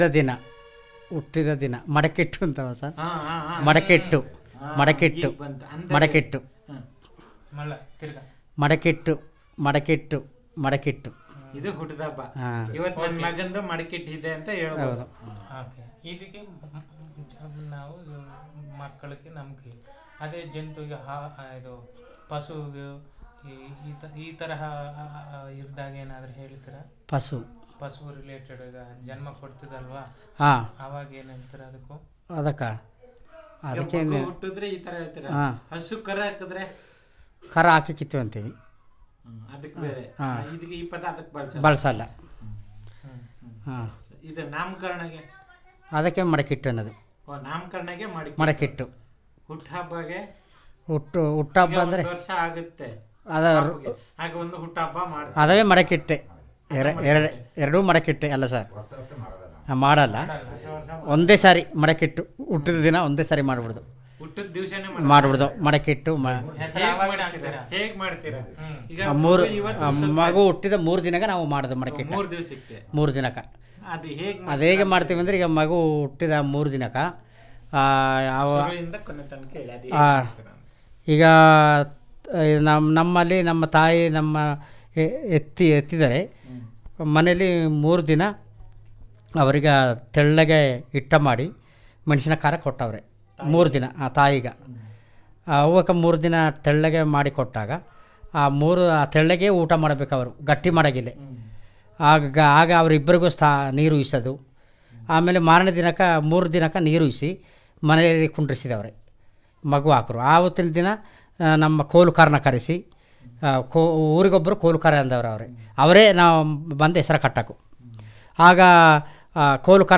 Personal Narrative on Naming Ceremony